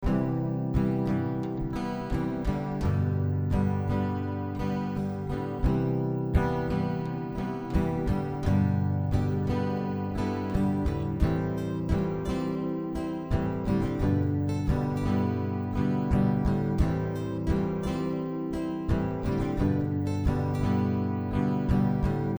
ループ可能
Acousticアコースティック音源